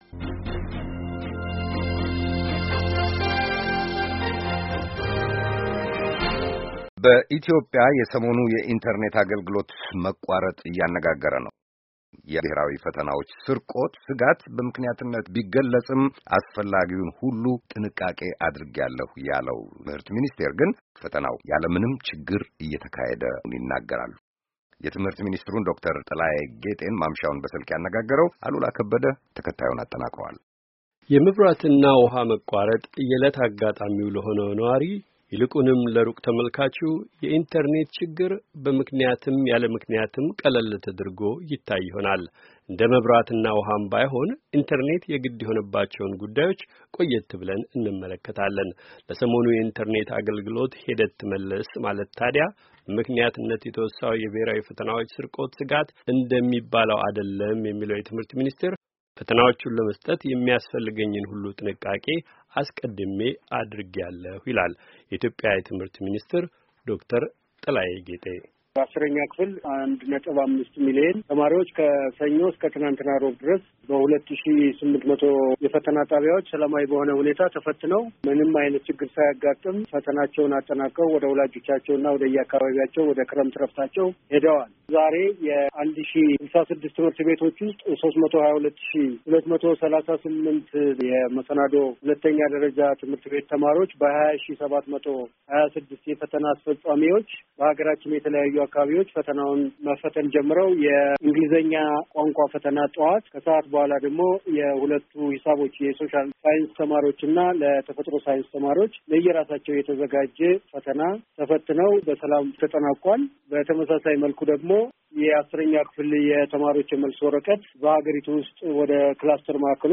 የትምሕርት ሚንስትሩን ዶ/ር ጥላዬ ጌጤን በስልክ አነጋግረናል።